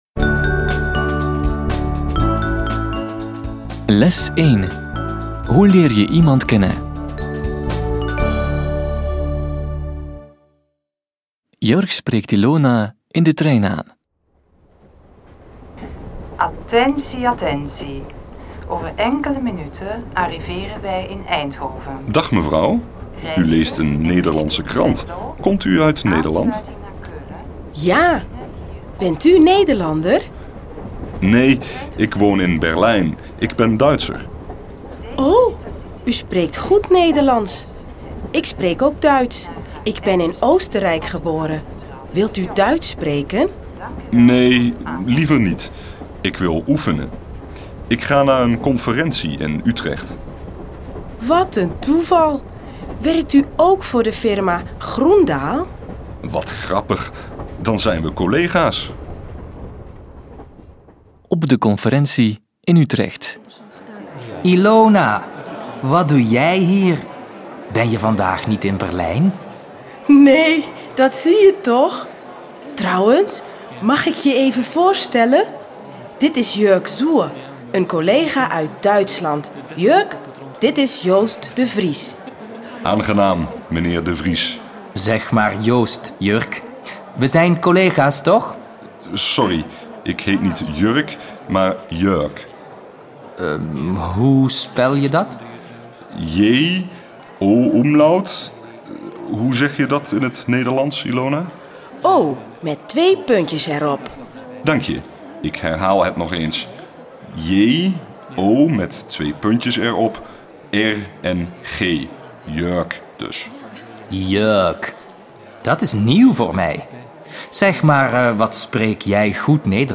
3 Audio-CDs mit Dialogen in zwei Sprechgeschwindigkeiten und abwechslungsreichem Hör- und Sprechtraining